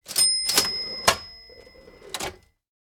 Звуки дзынь
На этой странице собраны разнообразные звуки «дзынь» — от легких металлических перезвонов до игривых хрустальных ноток.